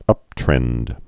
(ŭptrĕnd)